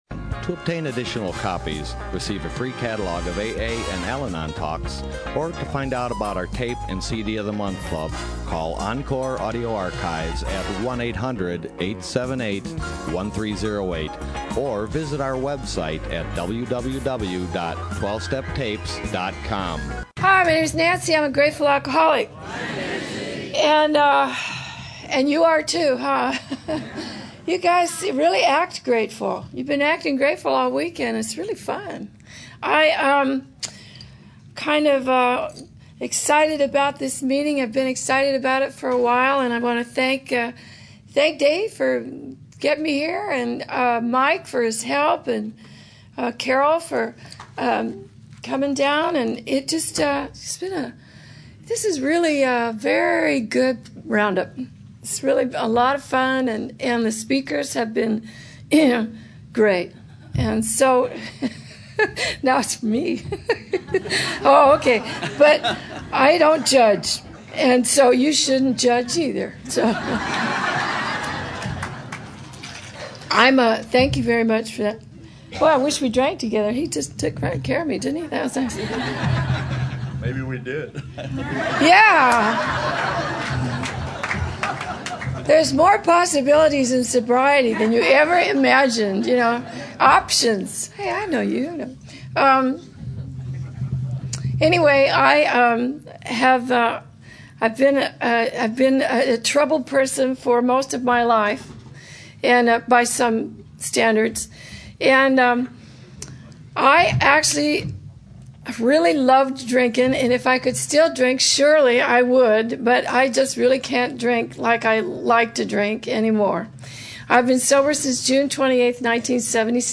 SOUTHBAY ROUNDUP 2010